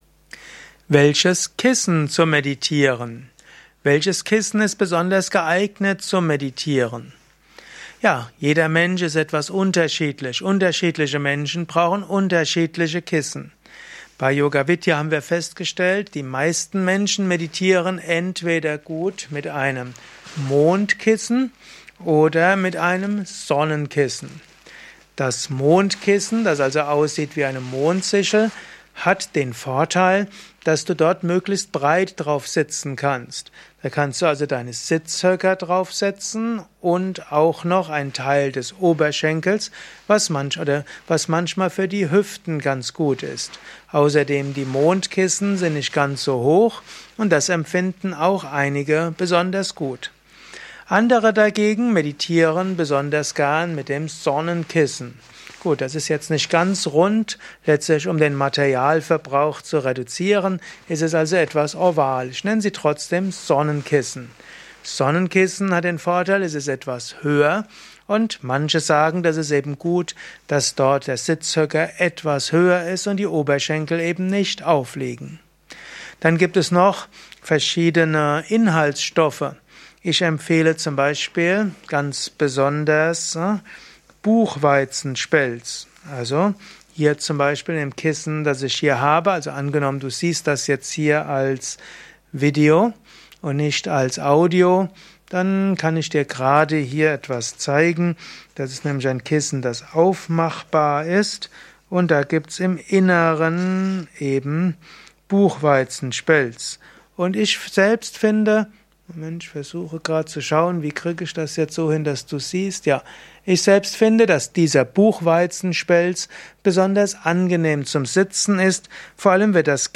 Einige Infos zum Thema ” Welches Kissen zum Meditieren?” durch dieses Vortragsaudio.